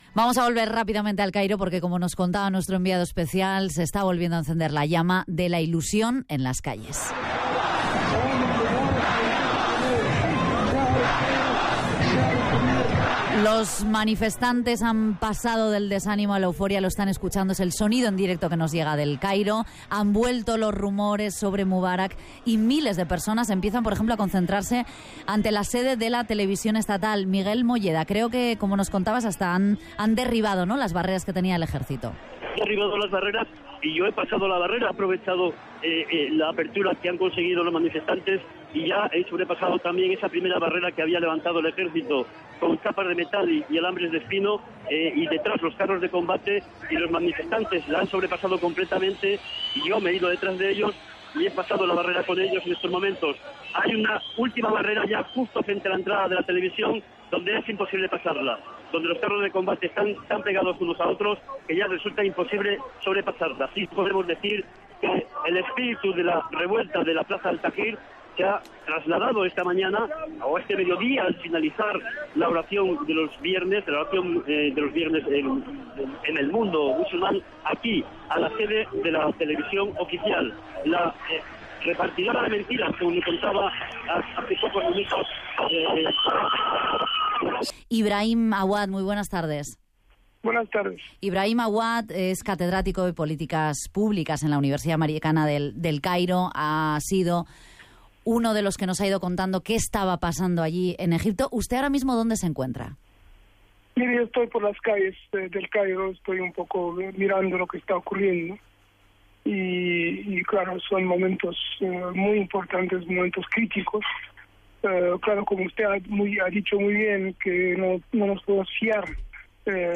A Egipte Hosni Mubàrak presenta la dimissió com a president d'Egipte com a conseqüència de la revolució egípcia començada el 25 de gener de 2011. Crònica des del Caire i trucada a un professor universitari per valorar la situació
Informatiu